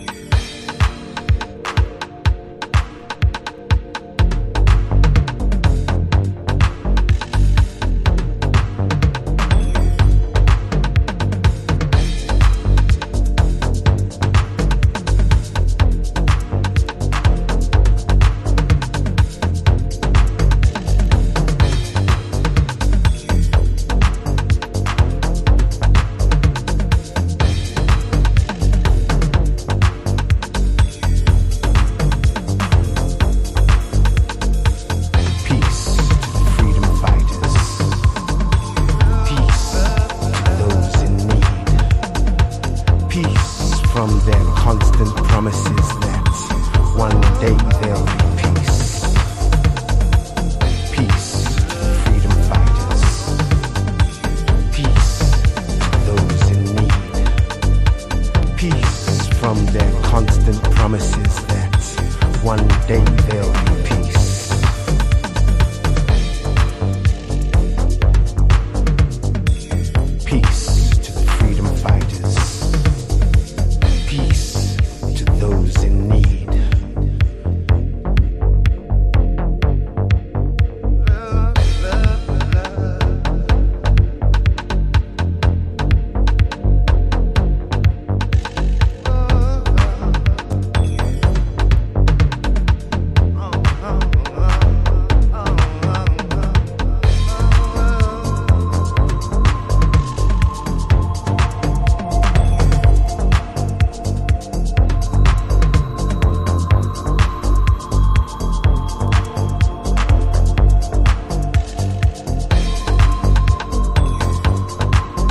Chicago Oldschool / CDH
Dub Mix